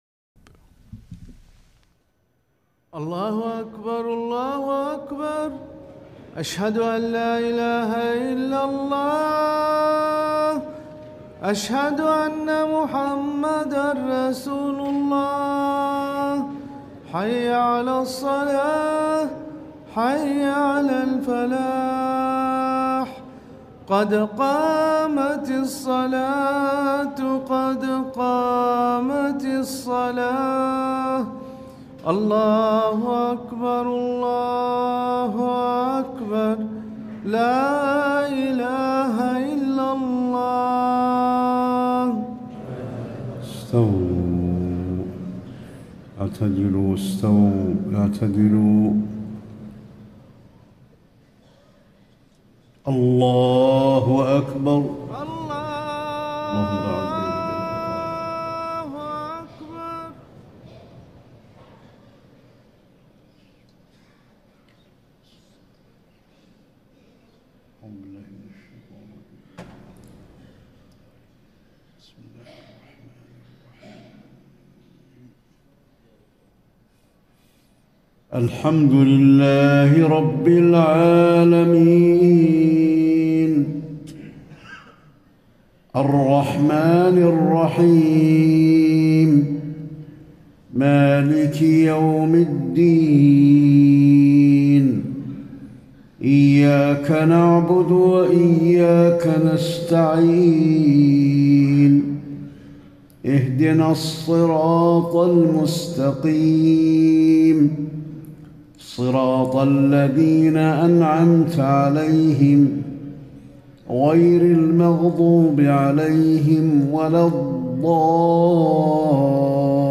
صلاة المغرب 1-6- 1435 سورتي التكاثر و الكافرون > 1435 🕌 > الفروض - تلاوات الحرمين